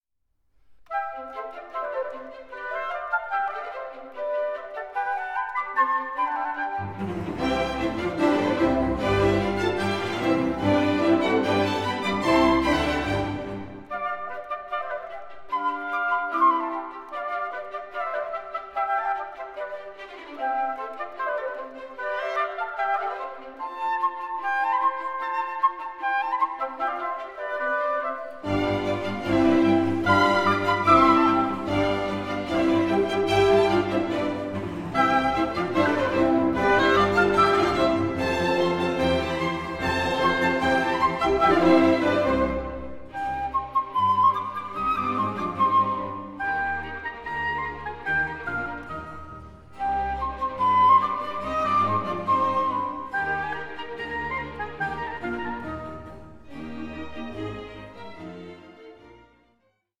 Allegro con brio 5:20